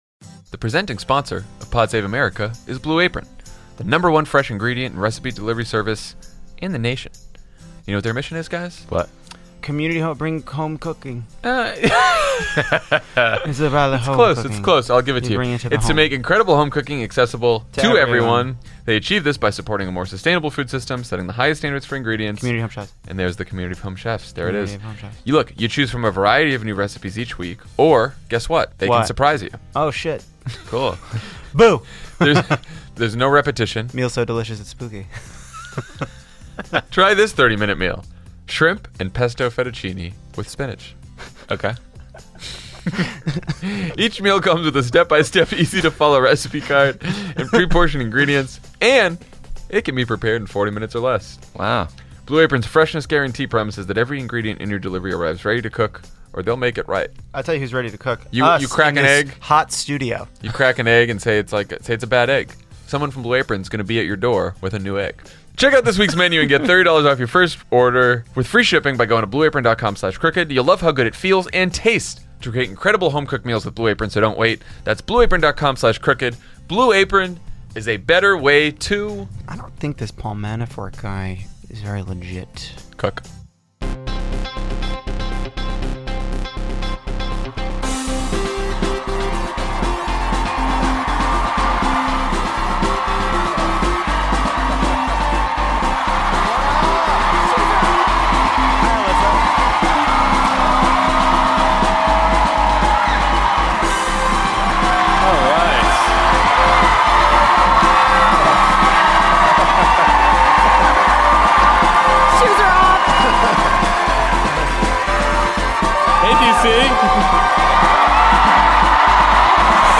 Of note from this past year is the Nov. 4, 2017, live episode from Washington, D.C., and the interview with former acting Attorney General Sally Yates. The episode includes one of the most amazing moments in my podcast year: the crowd reaction—its unrestrained roar of approval— to Yates.